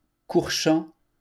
Courchamp (French pronunciation: [kuʁʃɑ̃]